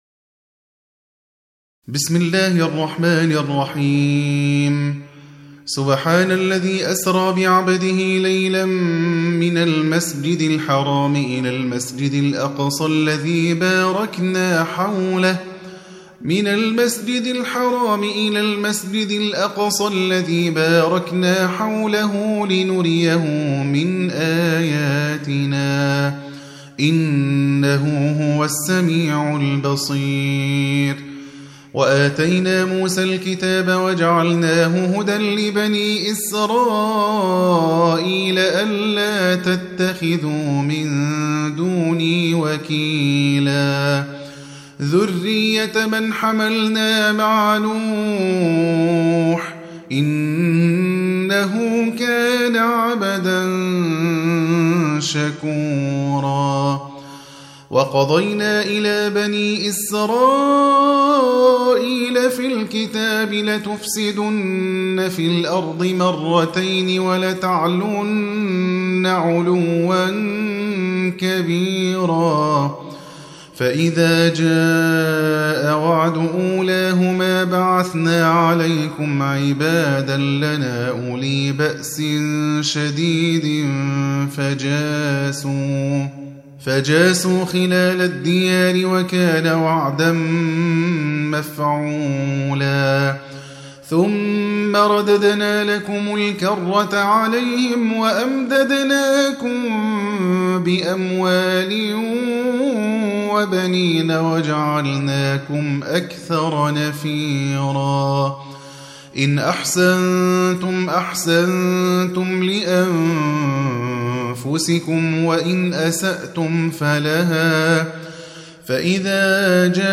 Surah Sequence تتابع السورة Download Surah حمّل السورة Reciting Murattalah Audio for 17.